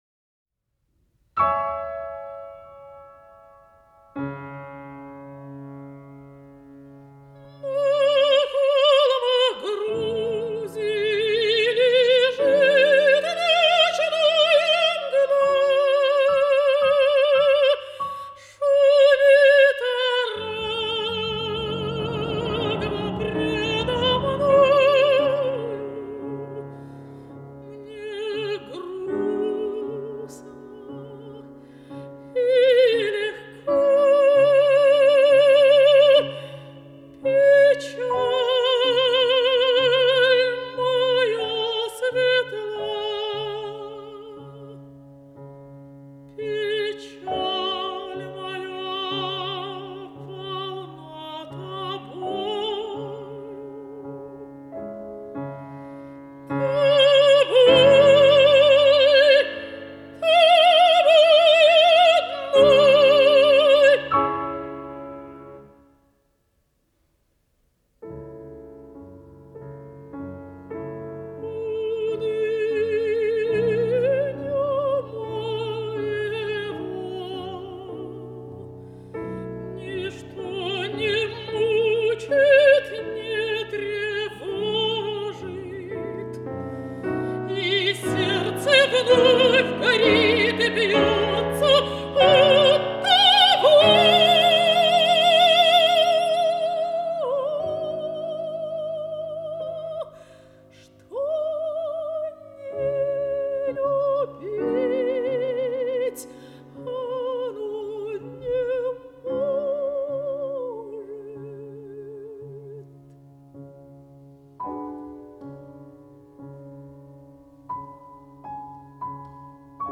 ф-но